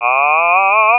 Vibrato and Glissando
Vibrato is shown using Ahh, as in the a in Father. In addition to putting vibrato on the sound (using an oscillator in CSound to vary the pitch), a ramp is used to gradually increase the level of vibrato (up to a semitone above to a semitone below the pitch of the note). The glissando is added to produce a slide upwards of one octave.
ahGlissVib.wav